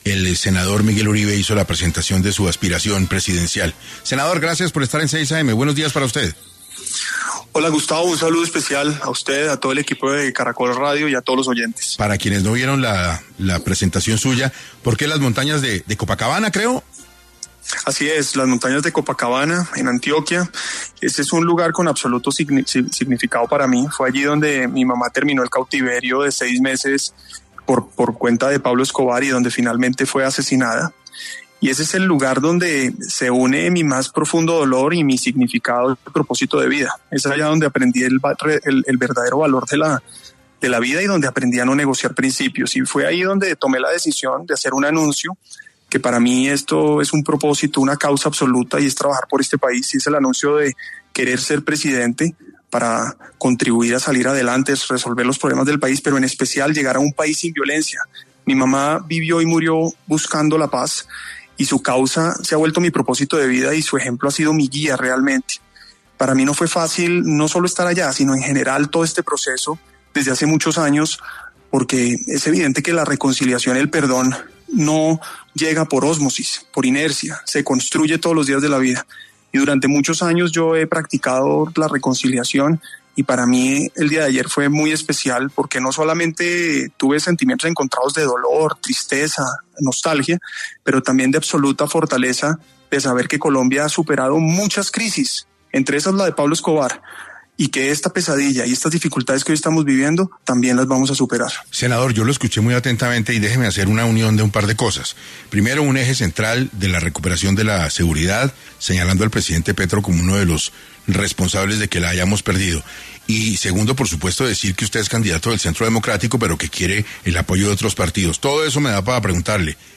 El senador Miguel Uribe estuvo en 6AM para abordar lo que fue el anuncio de su aspiración presidencial.